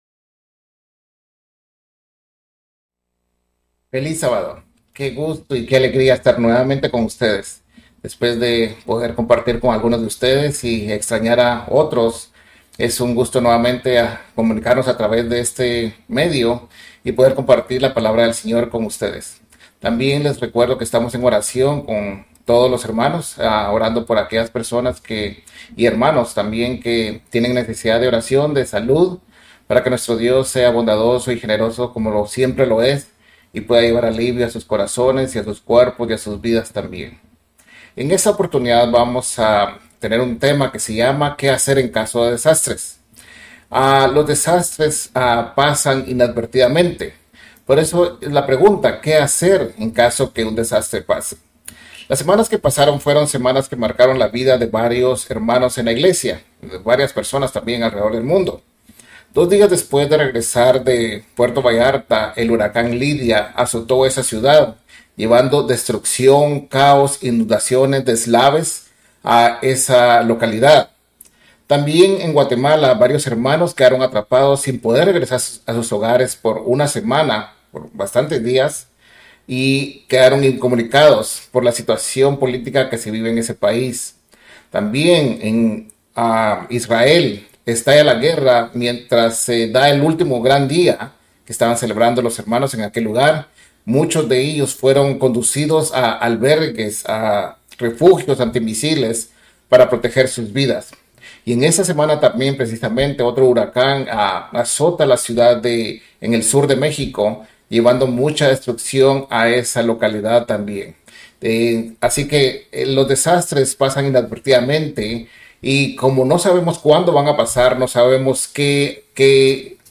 Mensaje entregado el 28 de octubre de 2023.